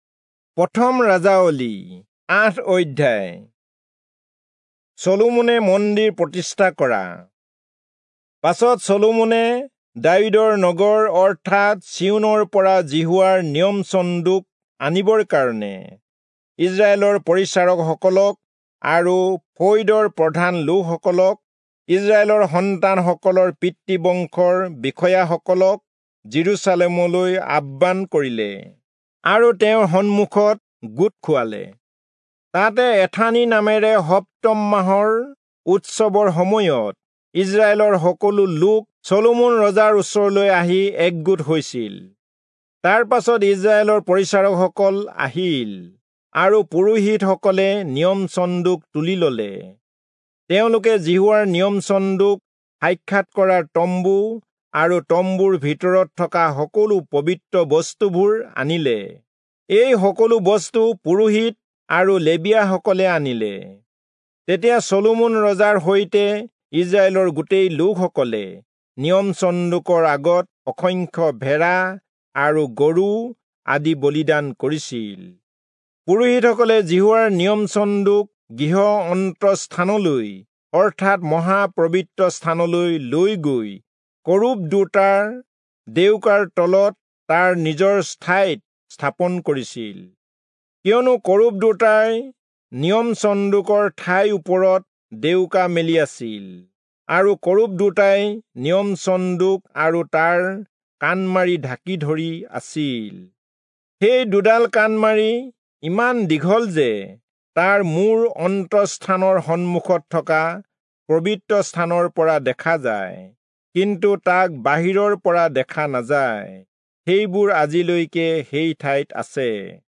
Assamese Audio Bible - 1-Kings 12 in Ervpa bible version